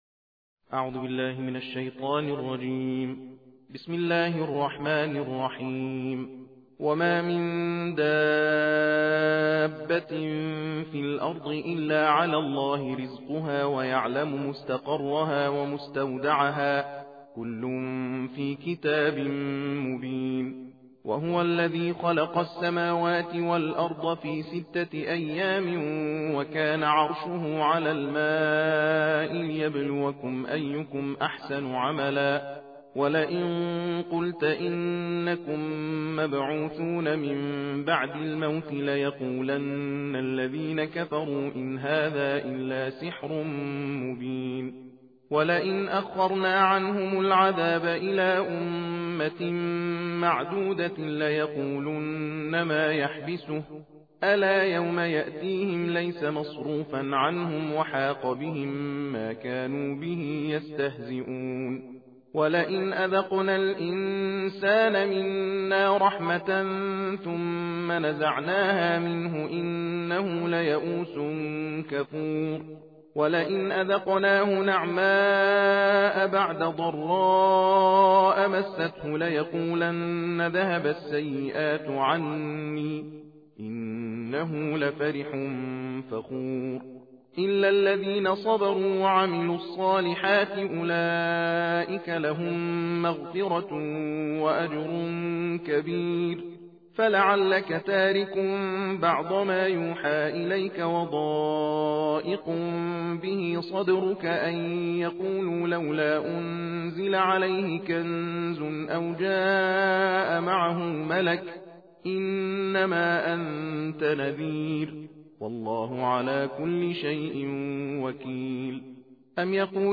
تحدیر «جزء دوازدهم» قرآن کریم